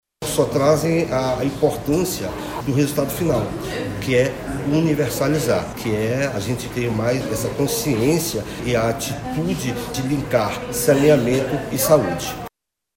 De acordo com o diretor-presidente da Companhia de Saneamento do Amazonas (Cosama), Denison Gama, a criação da Microrregião tem extrema importância para o trabalho do órgão e as suas operações no interior.